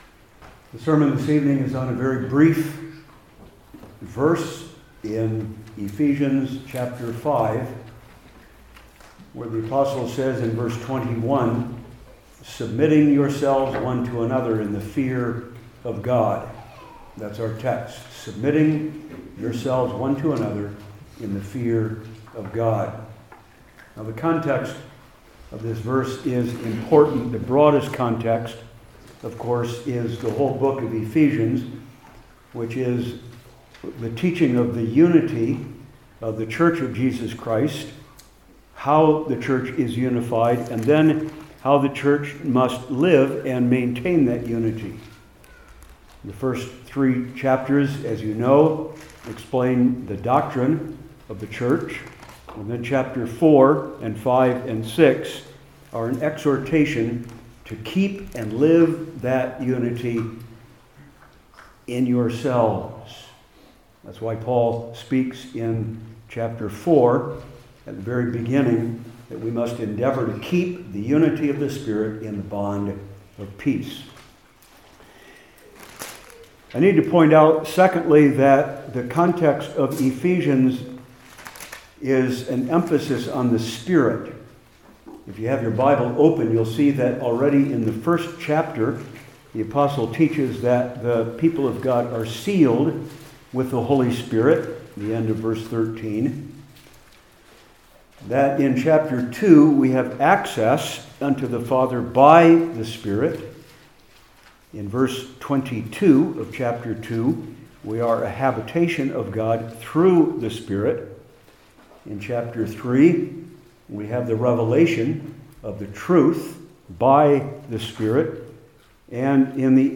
Old Testament Individual Sermons I. What This Means II.